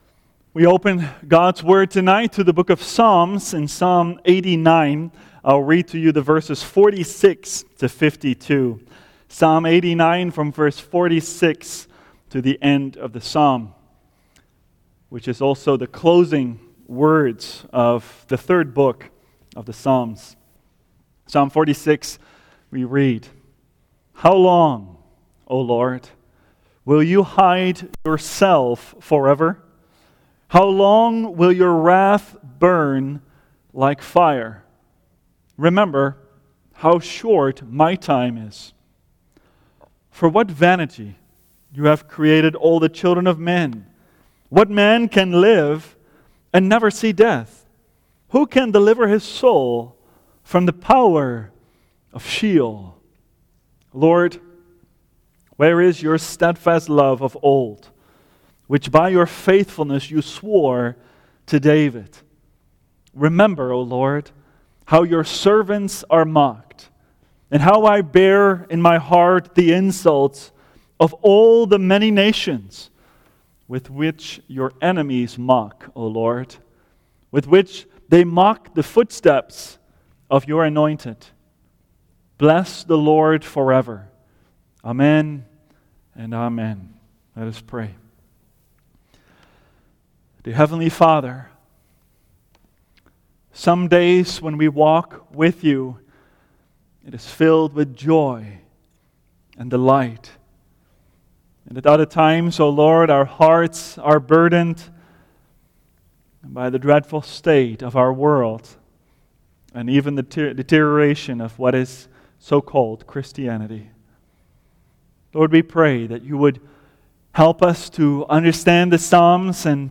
preaches